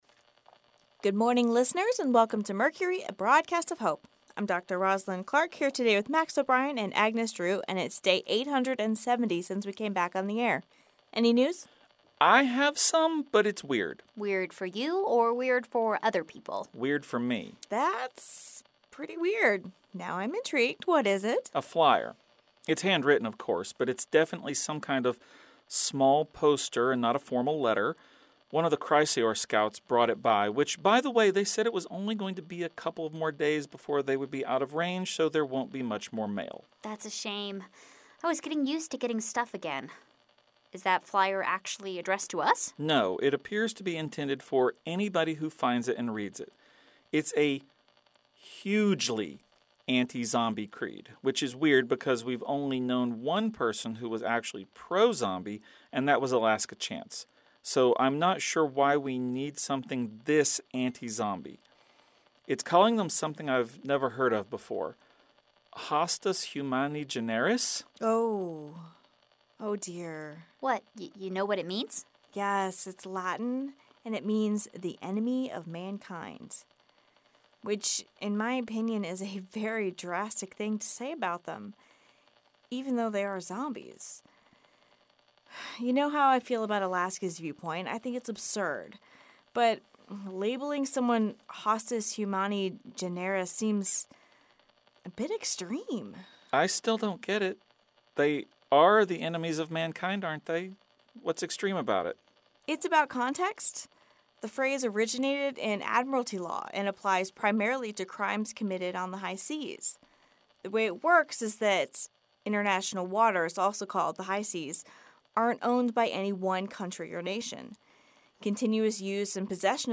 A daily audio theatre experience that tells the story of a small group of people living in a college radio station broadcasting during the zombie apocalypse. Rather than focusing on the horror and violence that is typical of the zombie genre, Mercury looks at it through the lens of trying to find hope and survival in a world where hope is scarce and survival is difficult.